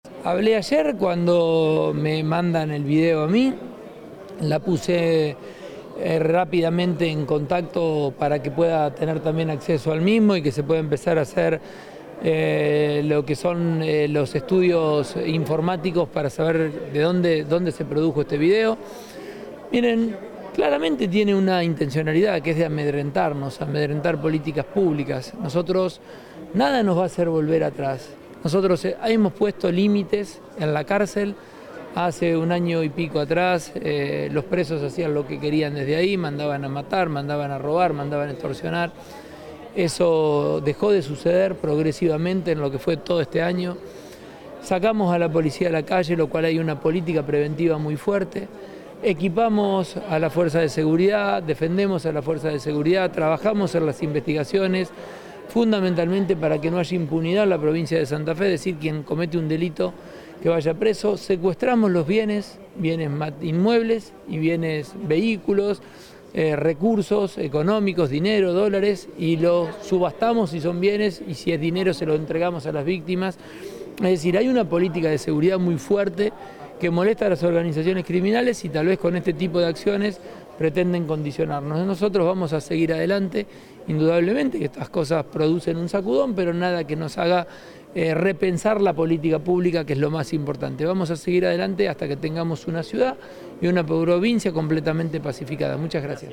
En declaraciones realizadas al finalizar el acto de entrega de escrituras a familias del departamento Rosario, el mandatario provincial precisó que esto se debe a que “hubo una caída de la violencia muy significativa en toda la provincia de Santa Fe, más en toda esta región, y particularmente en la ciudad de Rosario”; y recordó que “muchas veces en estos 11 meses, casi 12, nos quisieron quebrar con amenazas, y amedrentamientos y hasta con acciones terroristas, para que cambiemos nuestras políticas públicas”.
Declaraciones Pullaro